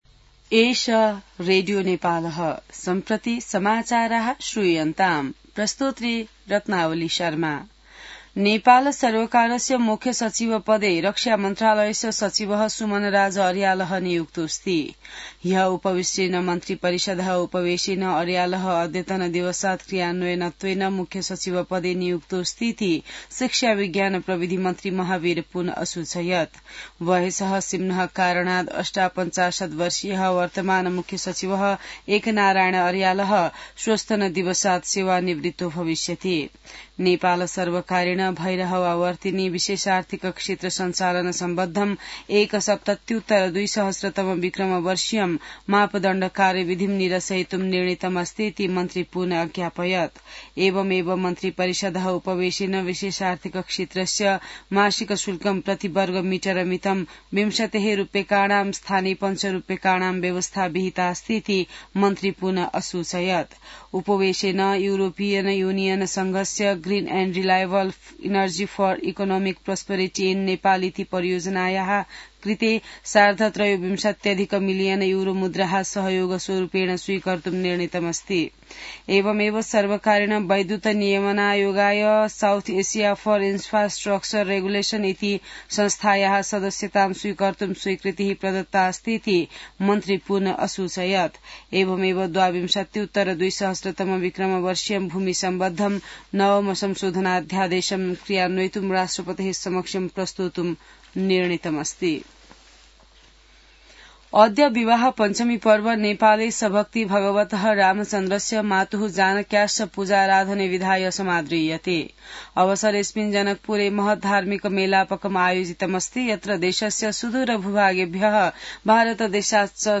संस्कृत समाचार : ९ मंसिर , २०८२